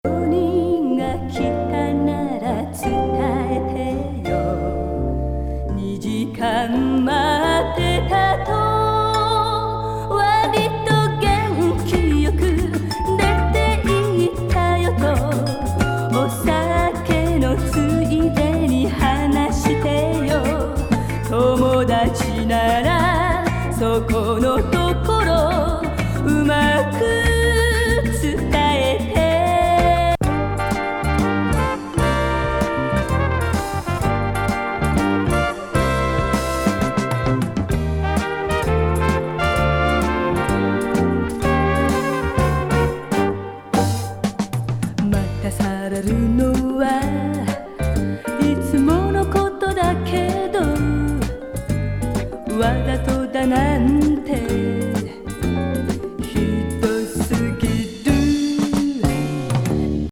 スローな曲調が魅力的な一枚。
ヴァイブの音色もドリーミンなクワイエット・リスニング歌謡
オルガンもエキゾチックな代名詞歌謡